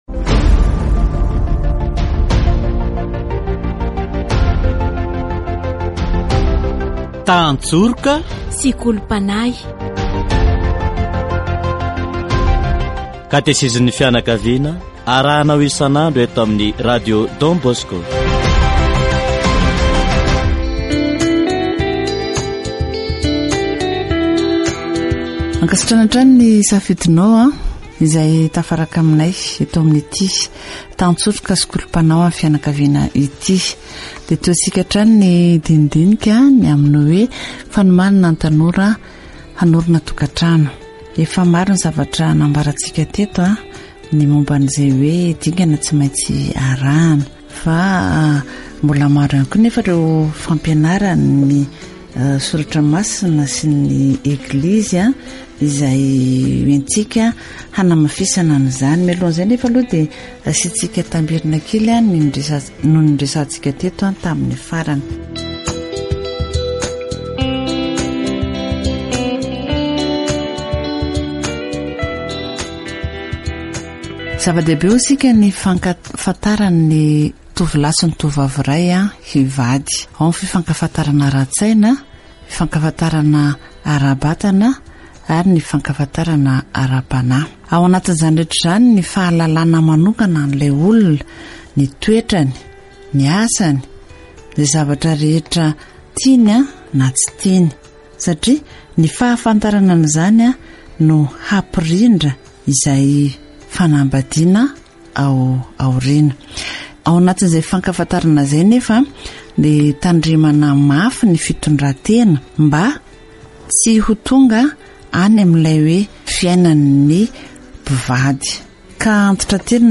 Love at first sight is not part of God's plan. Christians need to discover God's will so as not to be deceived by appearances, through prayer. Catechesis for young people preparing for marriage